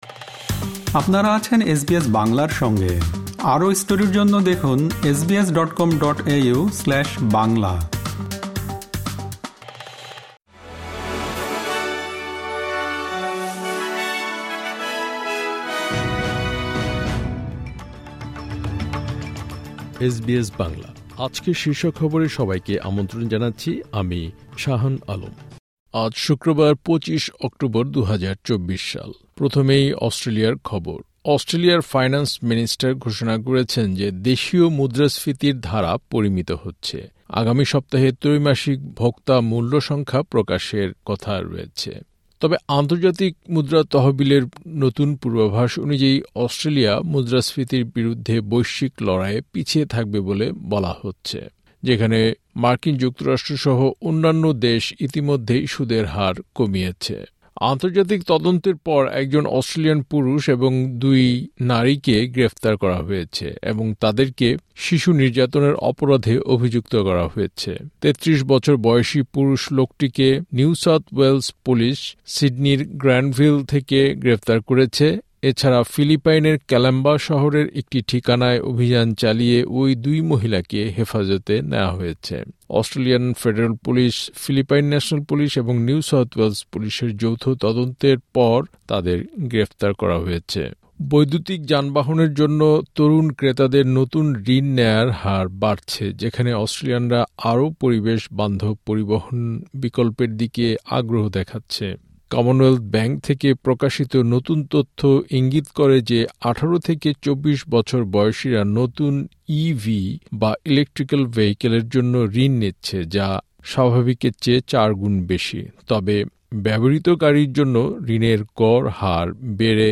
এসবিএস বাংলা শীর্ষ খবর: ২৫ অক্টোবর, ২০২৪